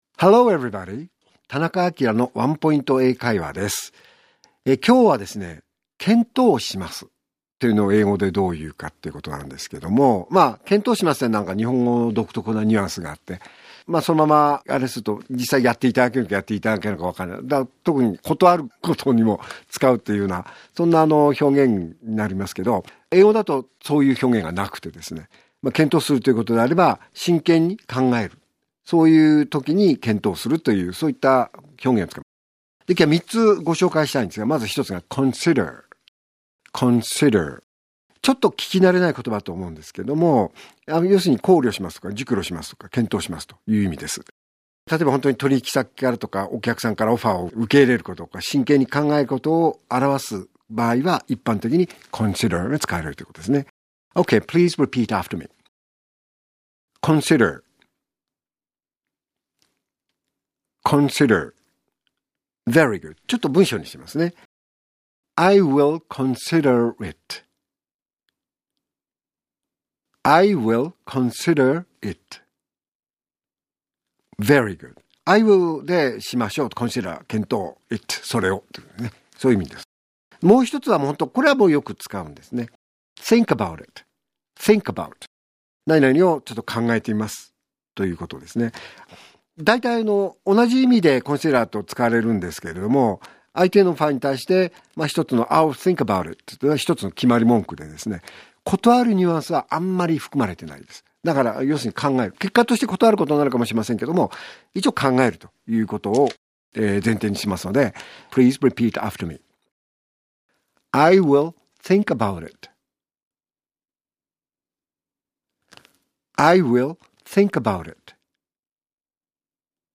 R6.1 AKILA市長のワンポイント英会話